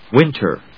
/wínṭɚ(米国英語), wíntə(英国英語)/